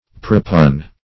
Search Result for " propugn" : The Collaborative International Dictionary of English v.0.48: Propugn \Pro*pugn"\, v. t. [L. propugnare; pro for + pugnare to fight.] To contend for; to defend; to vindicate.